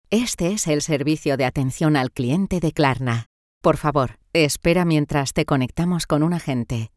Comercial, Cálida, Natural, Versátil, Empresarial
Telefonía